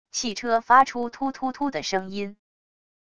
汽车发出突突突的声音wav音频